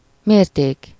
Ääntäminen
IPA : /ˈmɛʒ.əɹ/